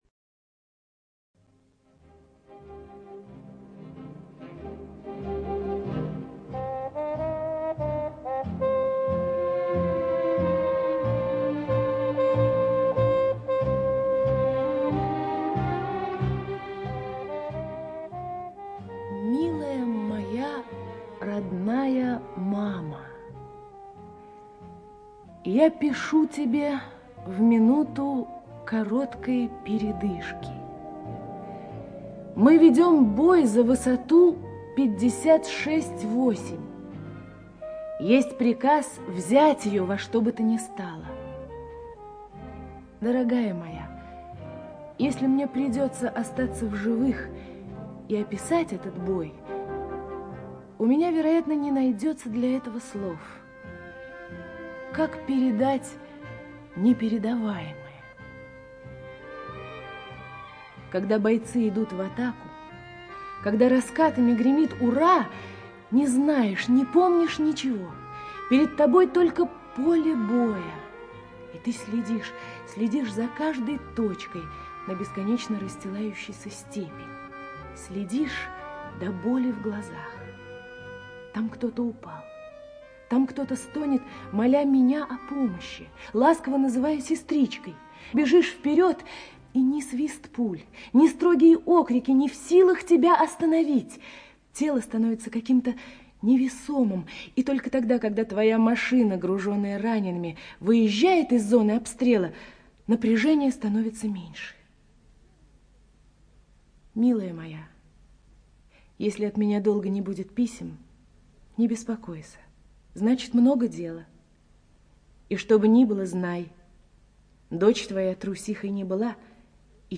ЖанрДетский радиоспектакль
Радиоспектакль по книге Елены Ильиной.
Студия записи/радиостанция: Главная редакция радиовещания для детей